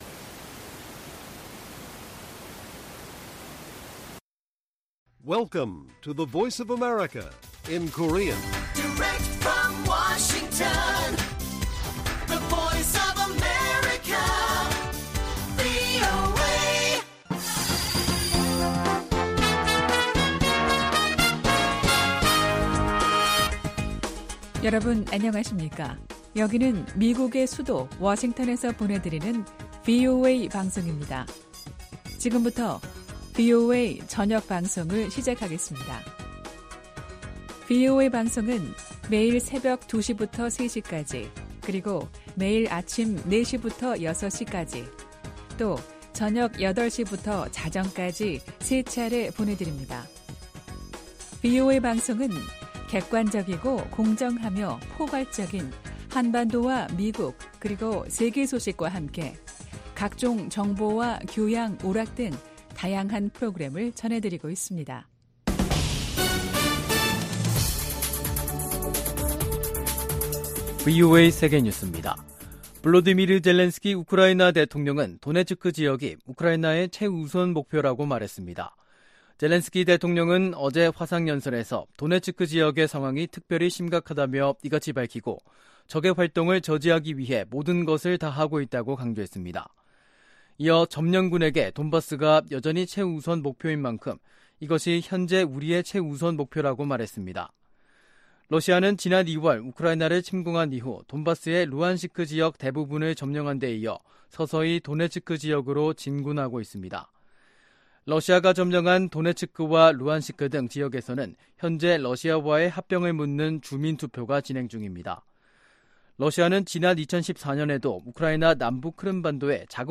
VOA 한국어 간판 뉴스 프로그램 '뉴스 투데이', 2022년 9월 27일 1부 방송입니다. 미 국무부가 북한 정권의 어떤 도발도 한국과 일본에 대한 확고한 방어 의지를 꺾지 못할 것이라고 강조했습니다. 카멀라 해리스 미 부통령과 기시다 후미오 일본 총리가 회담에서 북한 정권의 탄도미사일 발사를 규탄했습니다. 북한이 핵 개발에 쓴 비용이 최대 16억 달러에 달한다는 분석 결과가 나왔습니다.